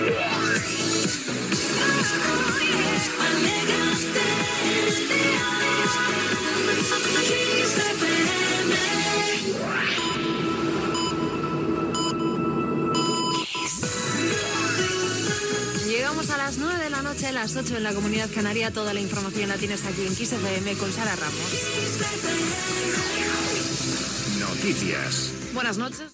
Indicatiu de la ràio